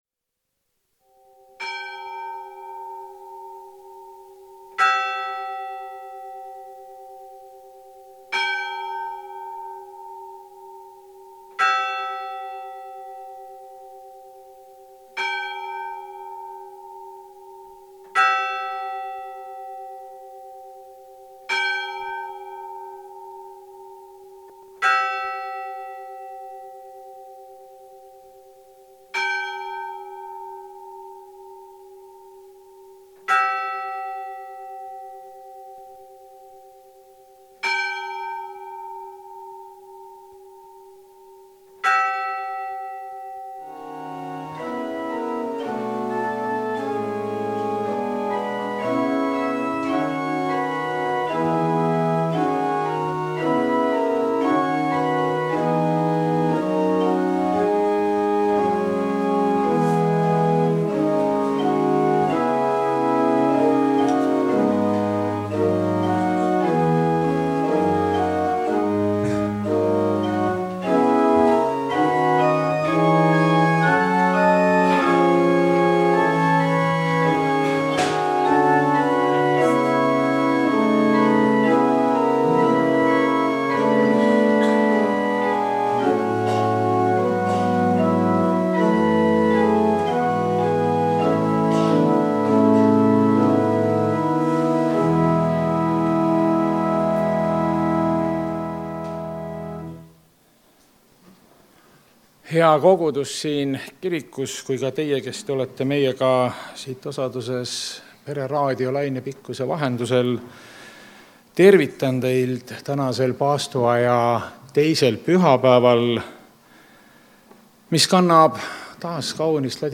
Jumalateenistus 1. märts 2026